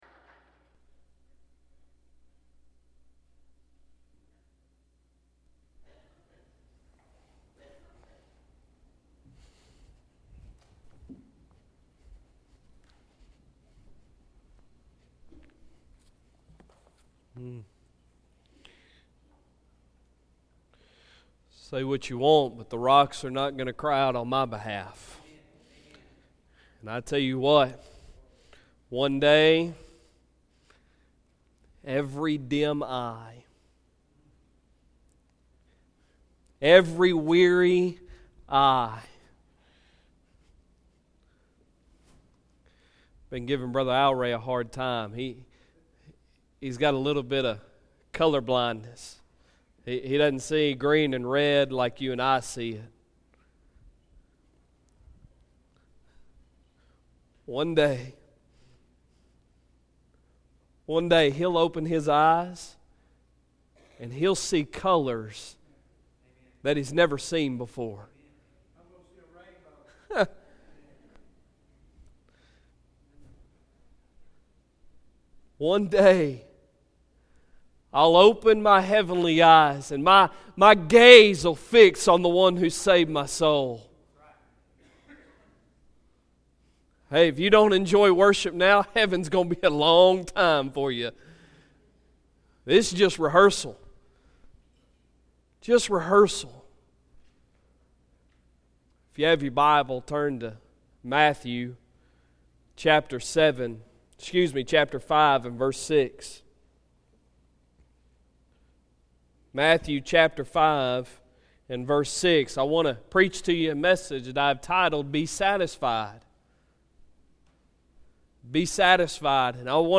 Sunday Sermon October 7, 2018 | Second Baptist Church
Sunday Sermon October 7, 2018